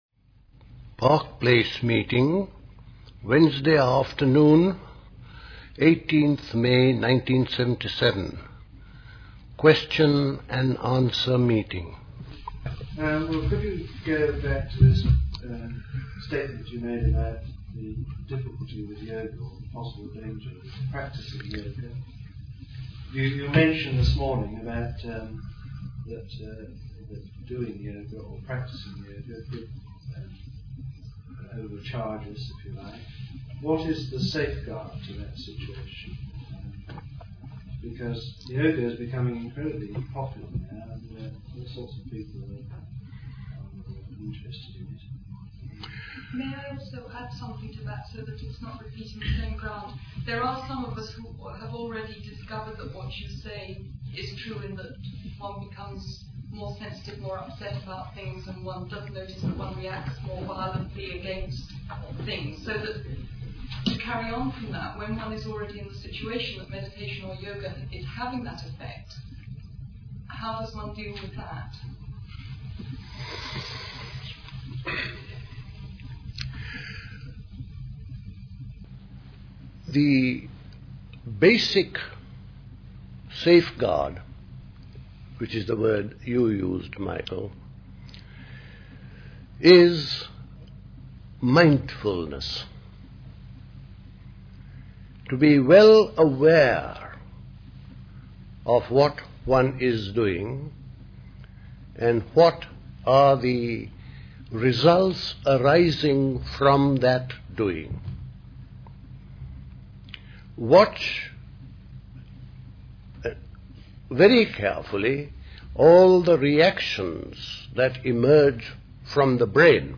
A talk
at Park Place Pastoral Centre, Wickham, Hampshire on 18th May 1977